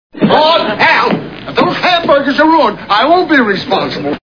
The Andy Griffith TV Show Sound Bites